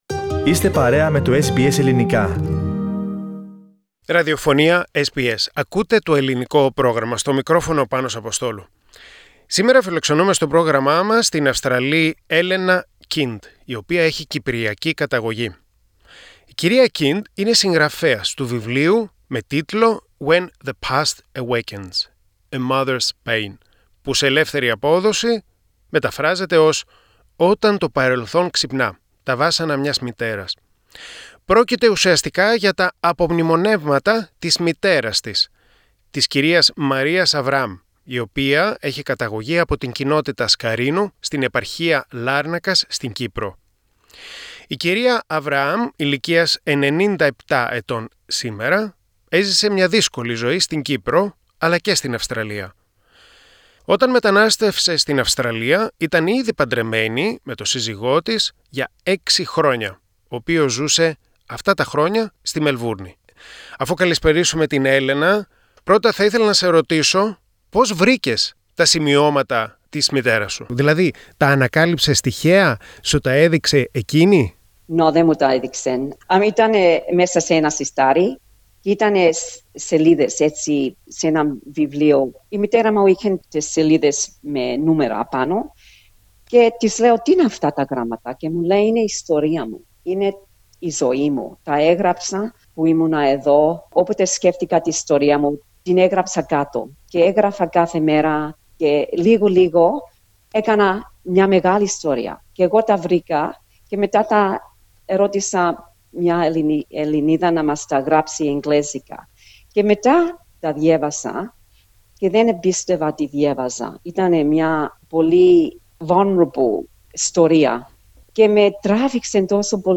SBS Greek View Podcast Series